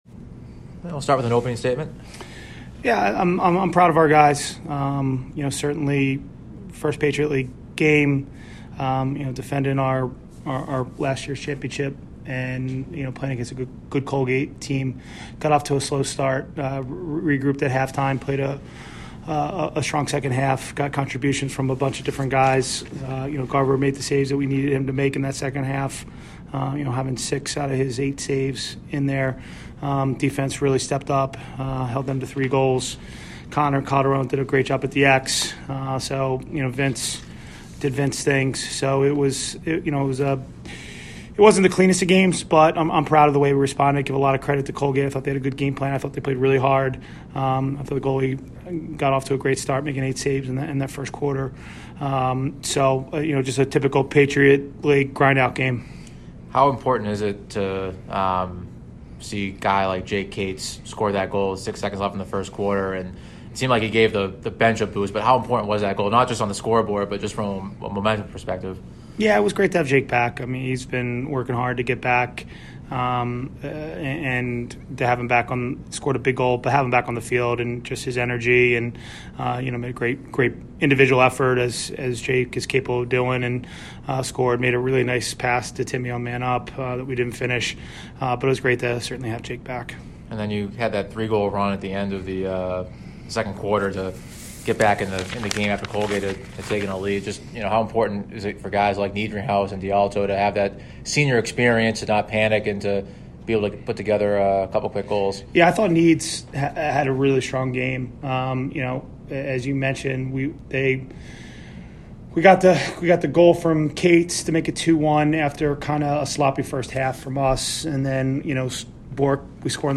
Colgate Postgame Interview